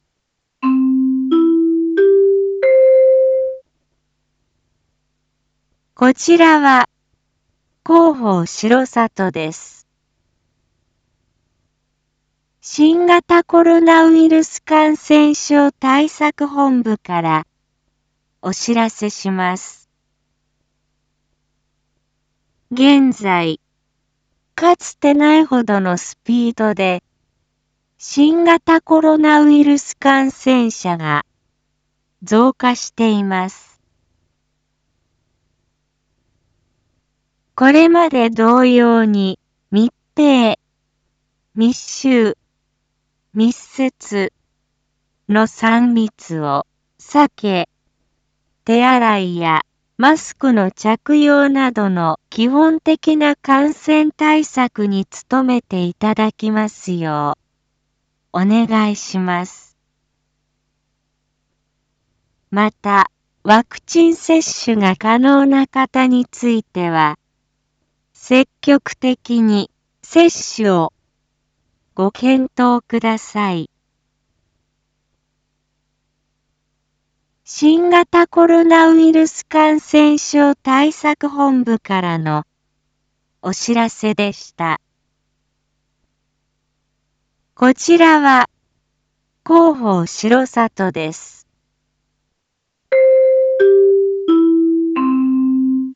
一般放送情報
Back Home 一般放送情報 音声放送 再生 一般放送情報 登録日時：2022-07-22 19:01:37 タイトル：R4.7.22 19時放送分 インフォメーション：こちらは広報しろさとです。 新型コロナウイルス感染症対策本部からお知らせします。